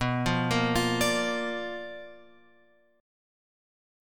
B Minor Major 7th Flat 5th